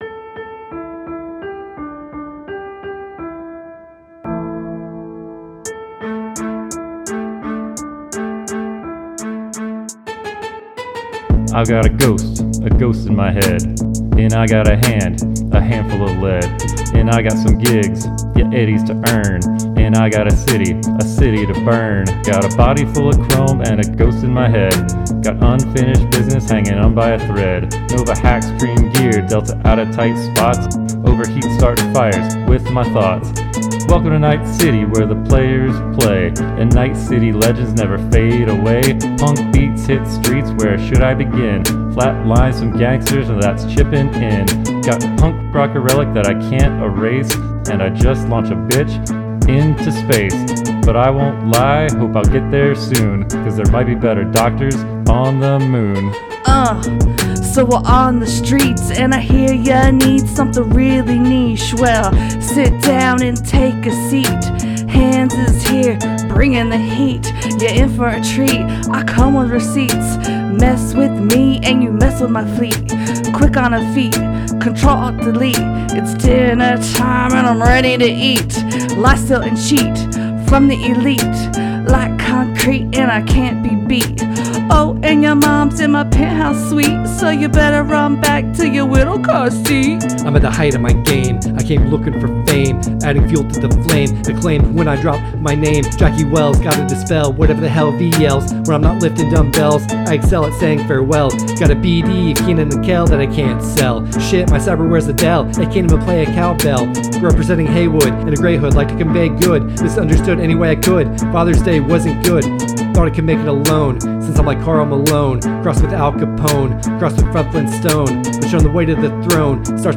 Rap from Episode 79: Cyberpunk 2077 – Press any Button
Cyberpunk-2077-rap.mp3